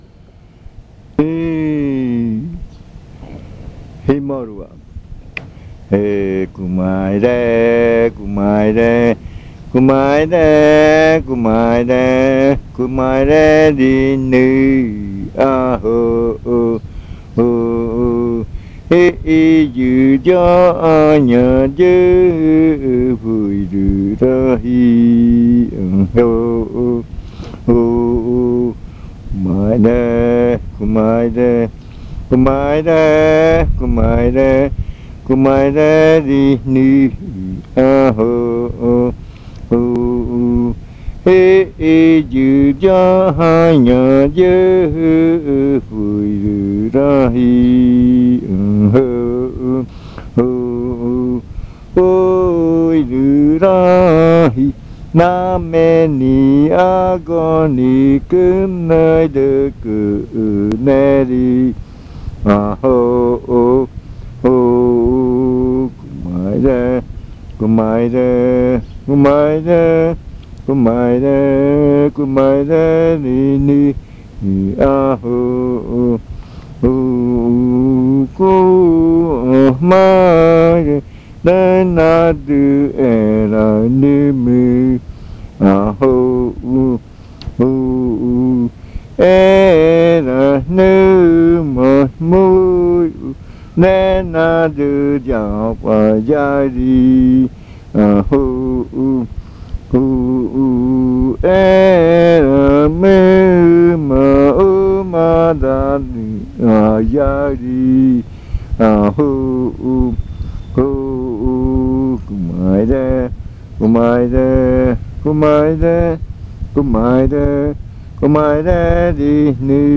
Leticia, Amazonas
Este canto hace parte de la colección de cantos del ritual Yuakɨ Murui-Muina (ritual de frutas) del pueblo Murui
This chant is part of the collection of chants from the Yuakɨ Murui-Muina (fruit ritual) of the Murui people